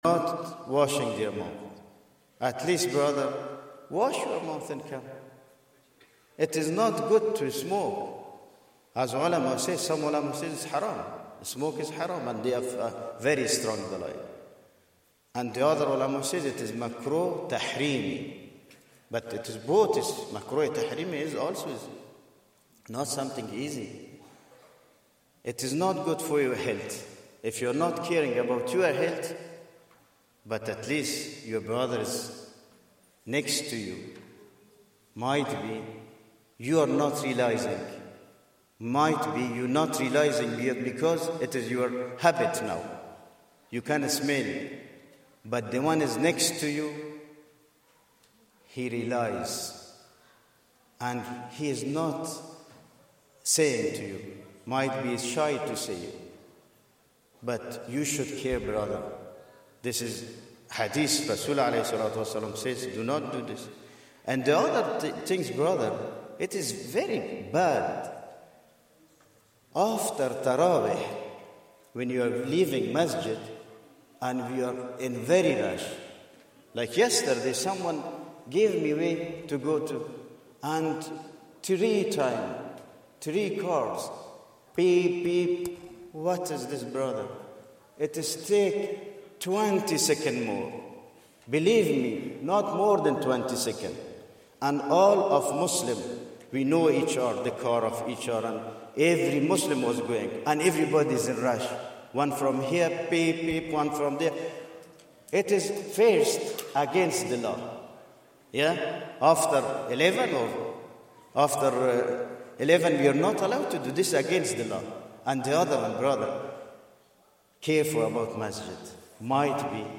Day 14 - Taraweeh Recital - 1445